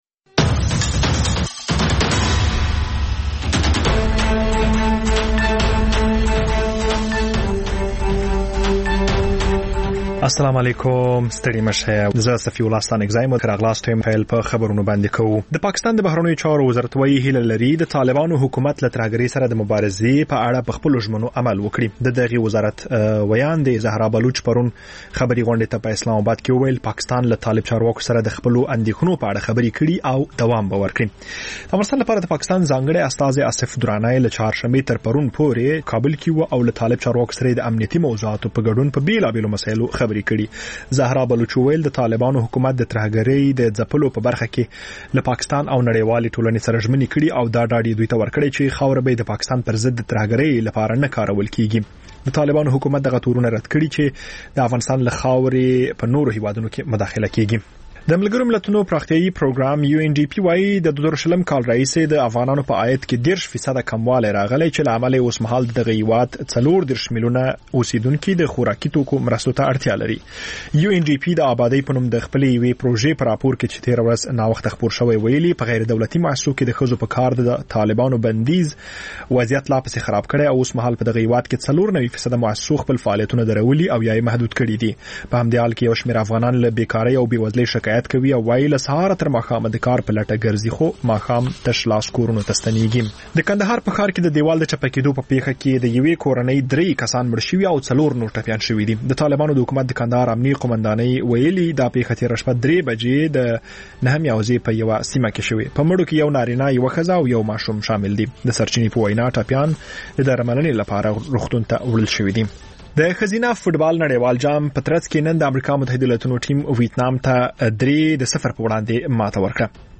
ماخوستنی خبري ساعت